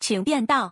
audio_lanechange.wav